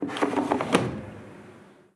Cerrar la puerta de una lavadora 1
lavadora
Sonidos: Acciones humanas
Sonidos: Hogar